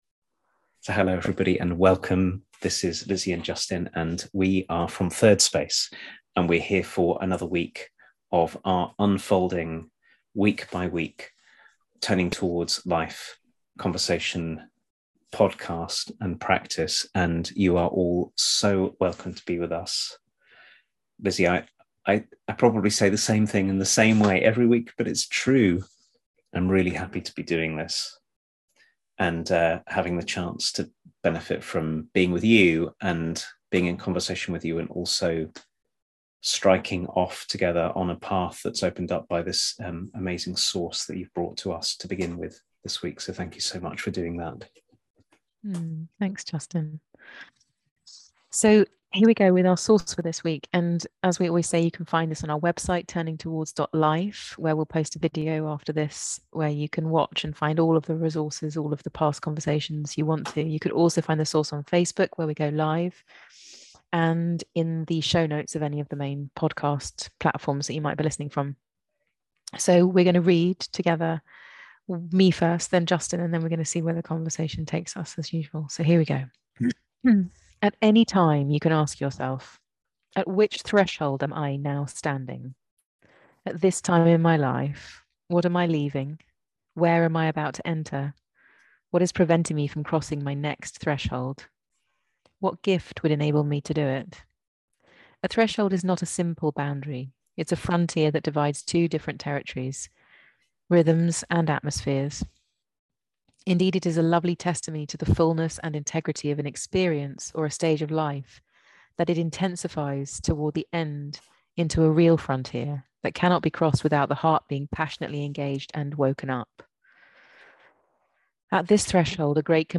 This week's Turning Towards Life is a conversation about the rituals we can create to mark life’s big changes and its more ordinary ones - sleep, waking, eating, the start and end of experiences, births, deaths, the moments when we arrive and the inevitable moments of moving.